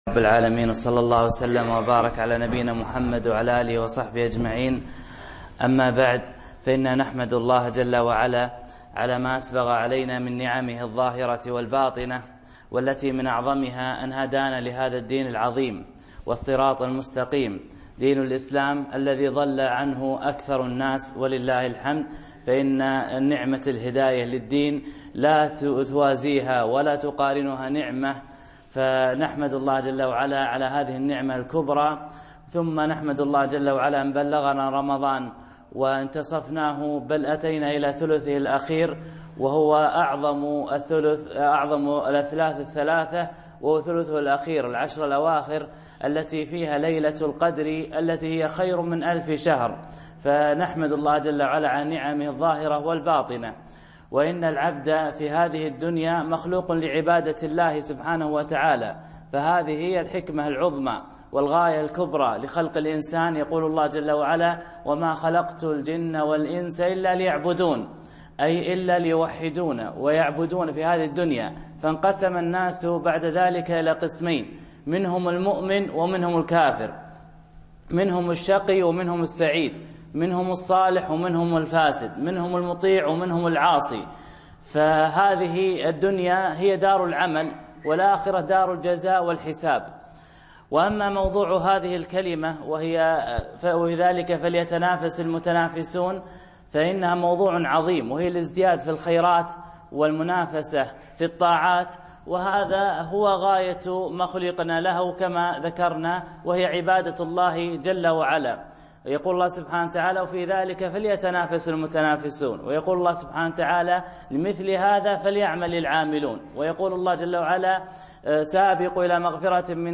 محاضرة - وفي ذلك فليتنافس المتنافسون